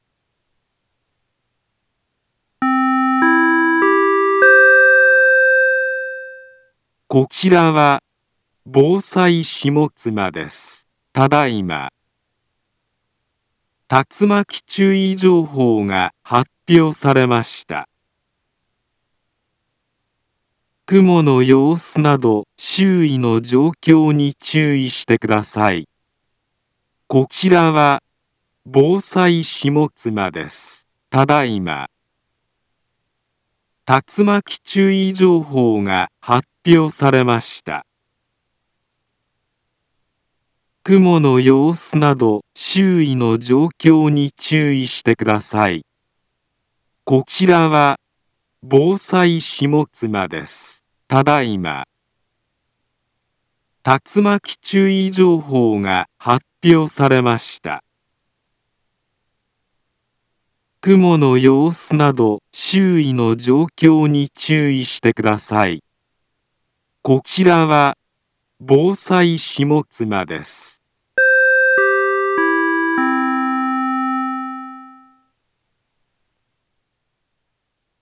Back Home Ｊアラート情報 音声放送 再生 災害情報 カテゴリ：J-ALERT 登録日時：2023-09-08 15:10:00 インフォメーション：茨城県北部、南部は、竜巻などの激しい突風が発生しやすい気象状況になっています。